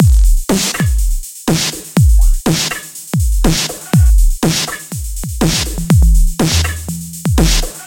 描述：一部手机的按钮序列变成了Tekno的声音
标签： 电话 奇怪的
声道立体声